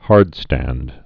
(härdstănd)